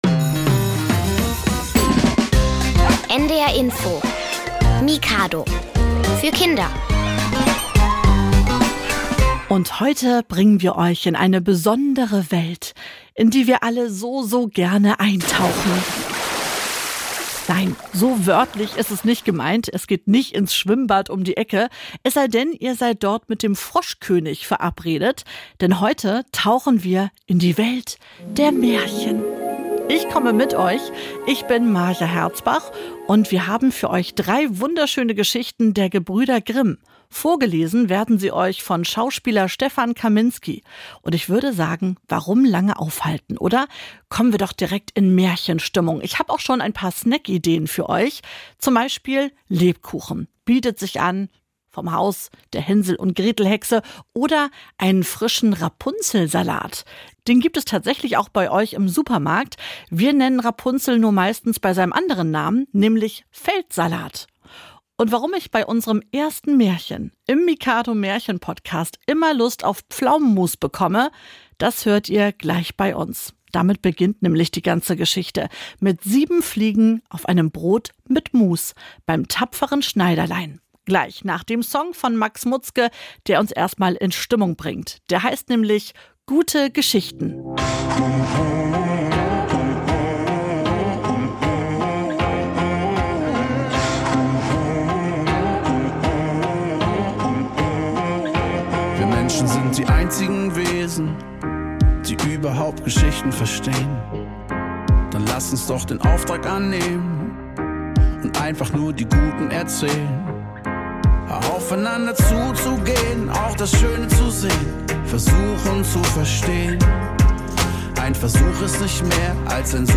Märchen Podcast (1) Schneiderlein/Aschenputtel/Geisslein RADIO (WE 52:54) ~ Hörspiele, Geschichten und Märchen für Kinder | Mikado Podcast